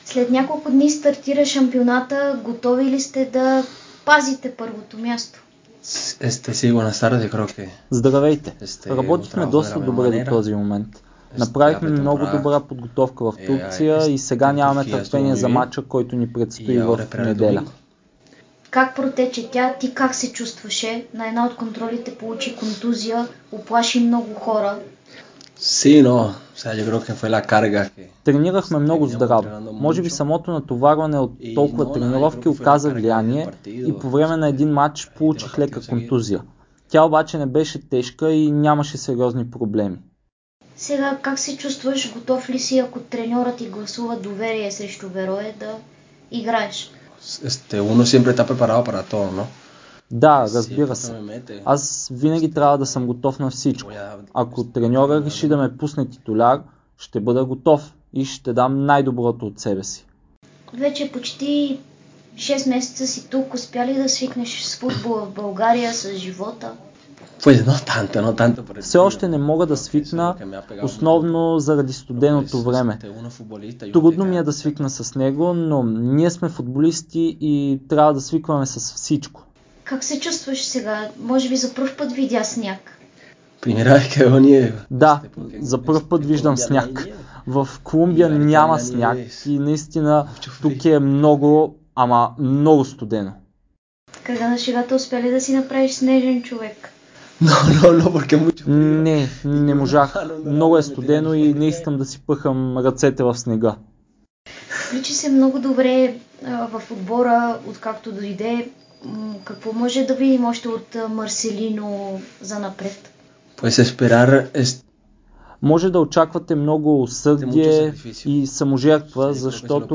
Колумбиецът даде ексклузивно интервю за Дарик радио и dsport преди подновяването на сезона в efbet Лига, при което за ЦСКА предстои гостуване на Берое в неделя (12 февруари), от 14:15 часа. Той говори за атмосферата в отбора, за целите и очакванията му за предстоящите мачове с червената фланелка.